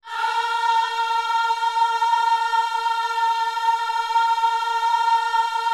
OHS A#4E  -R.wav